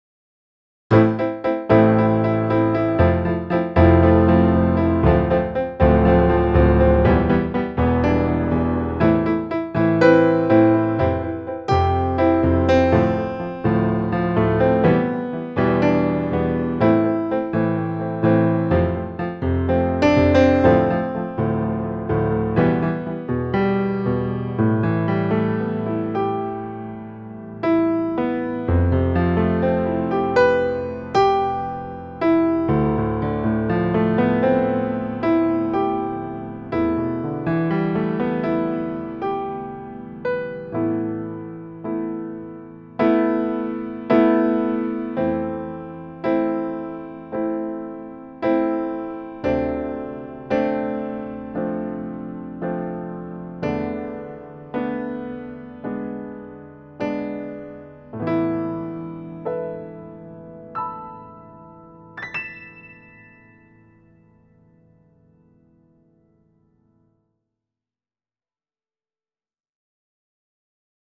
画面構成でいうと下側(トラックエディタ)に、楽譜がありますが、これは手で作成したものでなく、USB-MIDIインターフェースで接続して、音源を記録した際に自動で作成されたものです。
１.印刷するトラックを選択（画面イメージでの構成でいうと、"Grand Piano"の一つしかなく例が悪いですが）
piano3.m4a